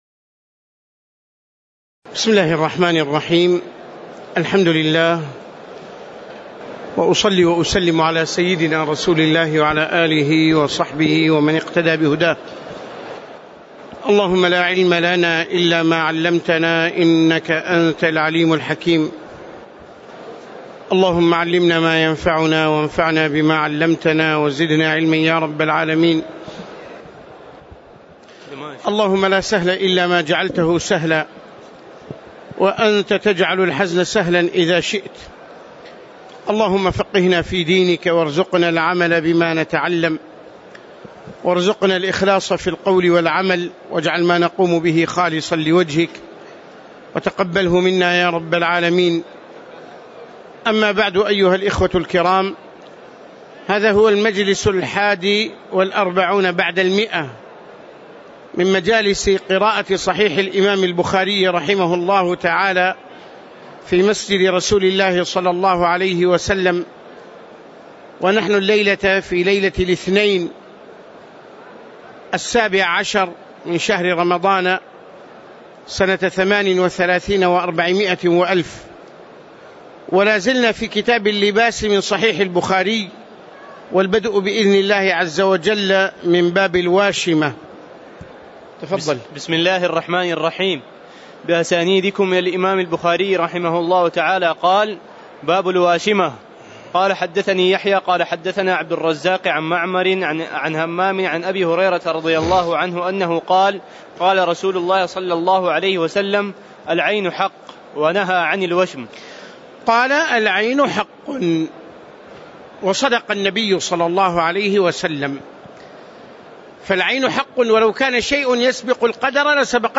تاريخ النشر ١٦ رمضان ١٤٣٨ هـ المكان: المسجد النبوي الشيخ